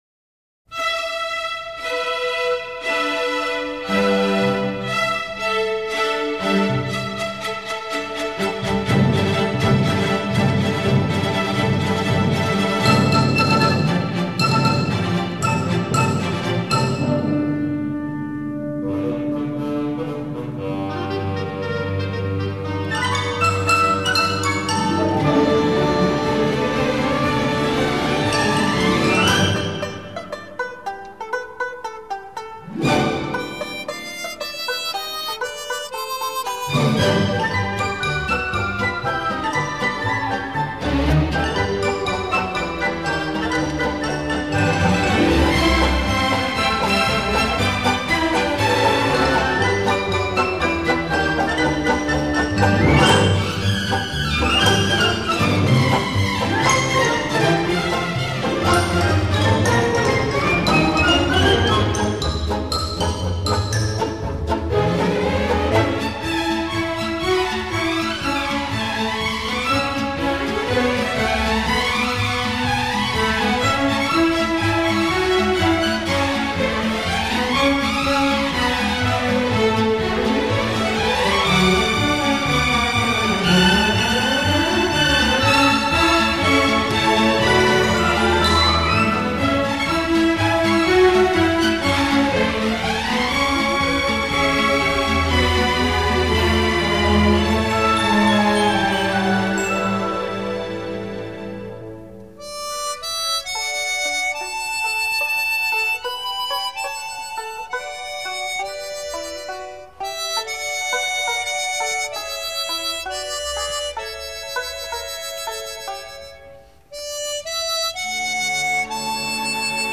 감상실4(성악곡) 세계의 민요
- 관현악
foster_oh_susanna_orchestra.mp3